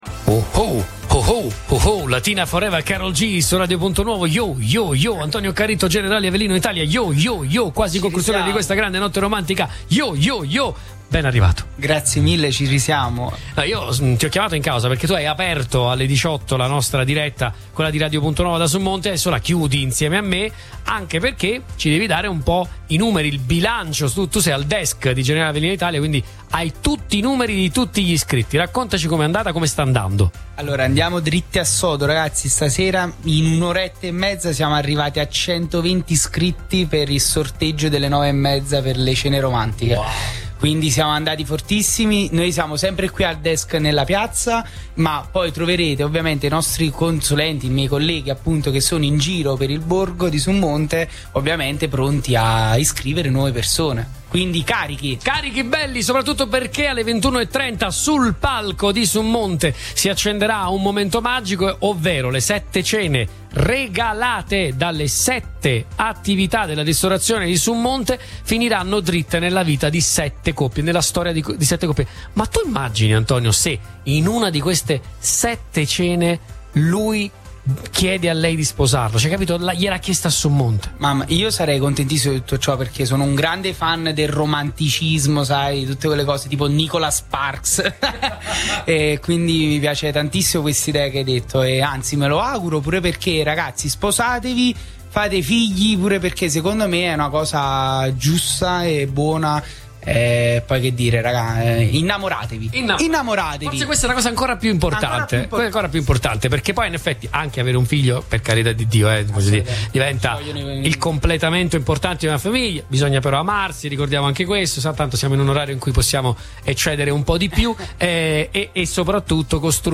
Sabato 21 giugno, in occasione del solstizio d’estate, il borgo di Summonte, tra i più belli d’Italia, ha ospitato una nuova edizione della Notte Romantica, evento patrocinato dal Comune di Summonte, in collaborazione con Generali Avellino Italia e Radio Punto Nuovo.